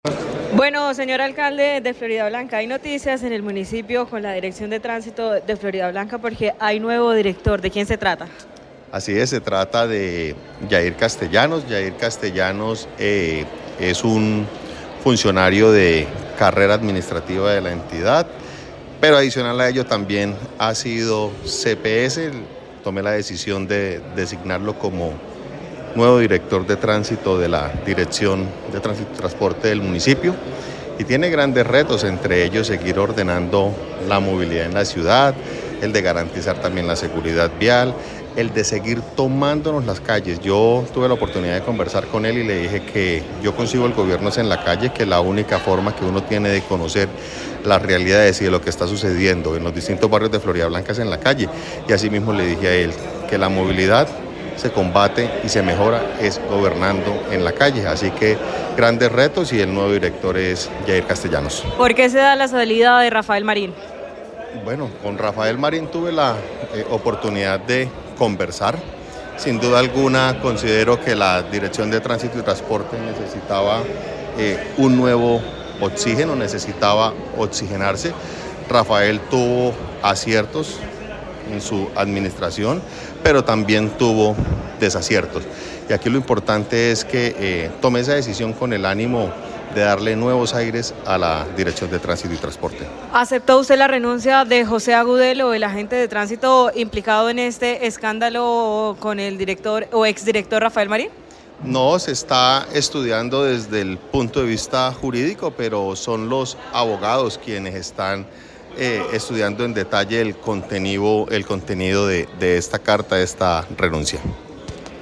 José Fernando Sánchez, alcalde de Floridablanca